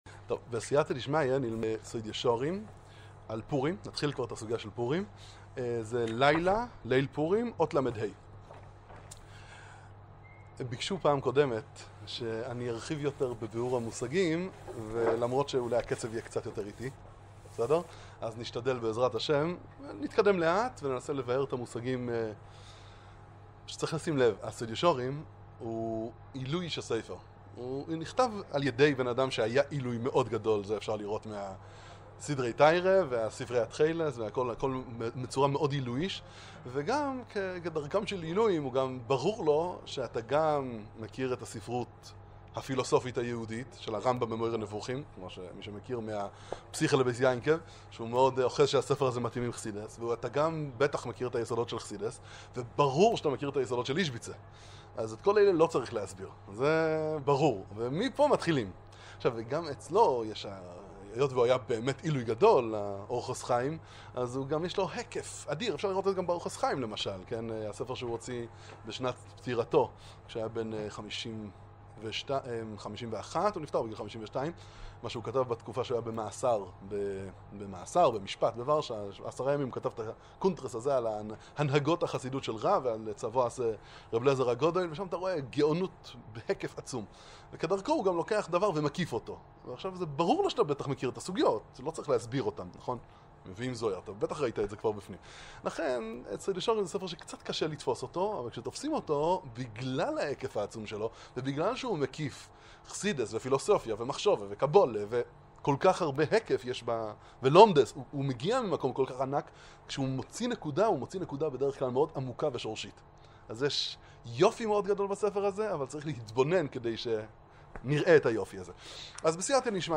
שיעור בתור איזביצה